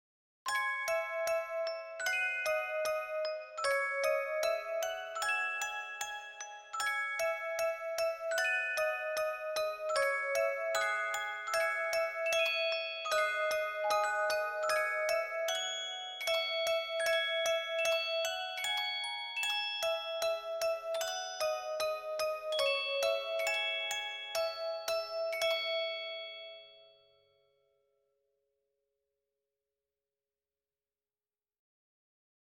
Dark arrange version of famous works
traditional song Germany